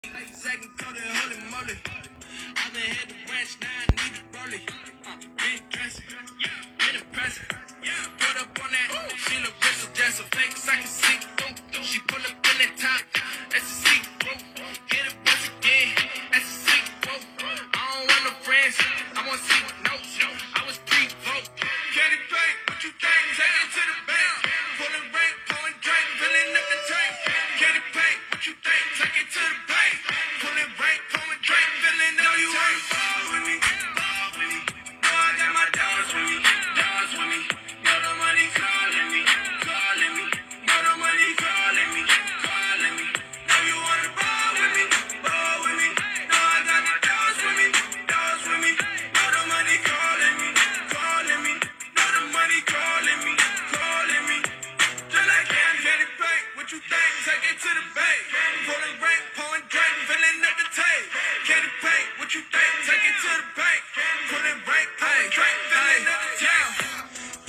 영어 발음도 일부 단어 빼고 안들리고
우연히 영상에 삽입된 노래인데  .. 능력자분 찾습니다.